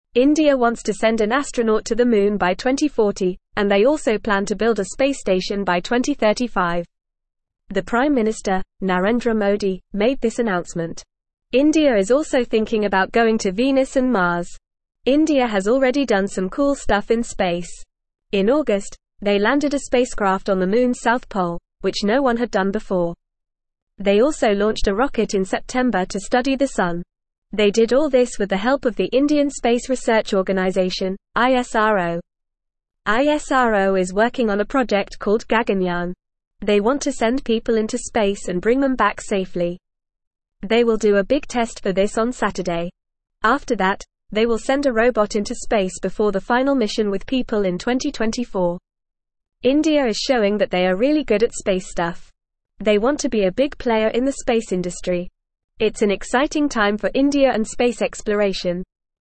Fast
English-Newsroom-Upper-Intermediate-FAST-Reading-Indias-Ambitious-Space-Goals-Moon-Mars-and-More.mp3